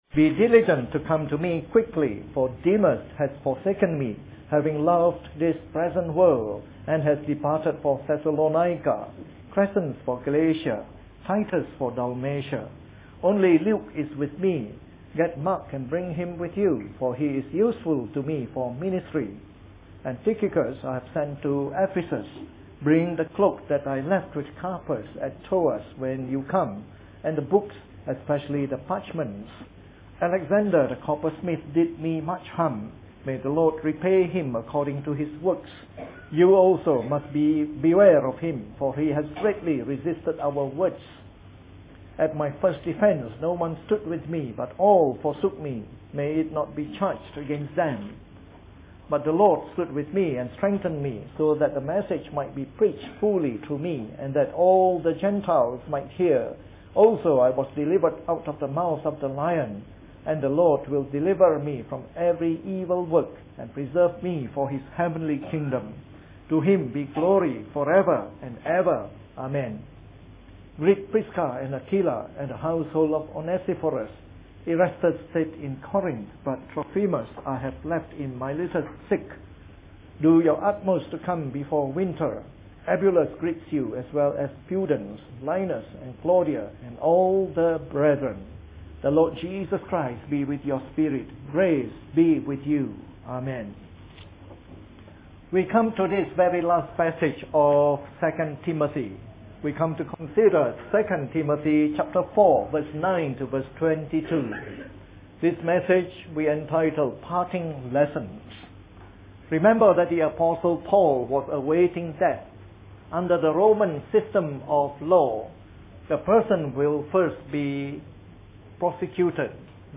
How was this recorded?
The final sermon from our series on 2 Timothy and the Pastoral Epistles, preached in the morning service.